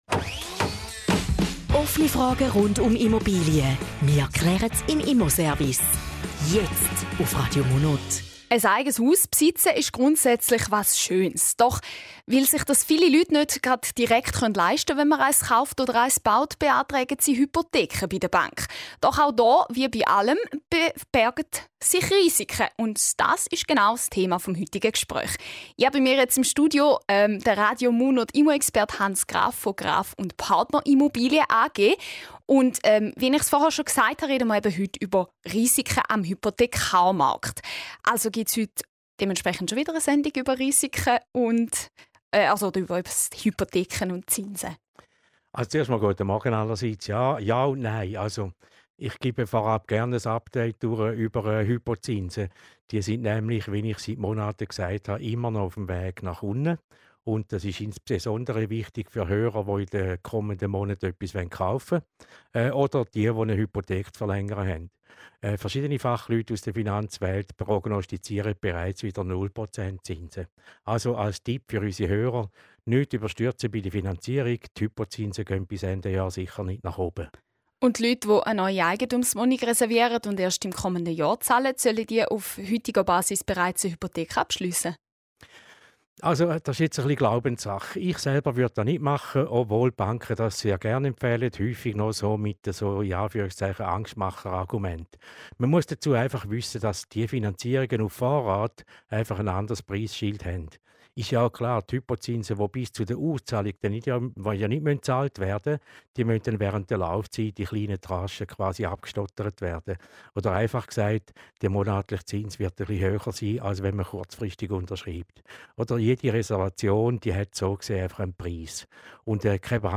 Zusammenfassung des Interviews zum Thema "Risiken am Hypothekarmarkt":